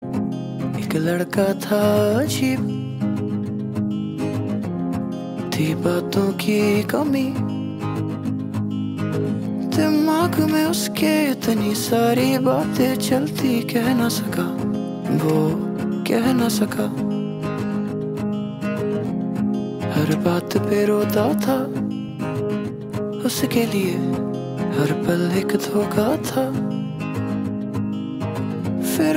Hindi Songs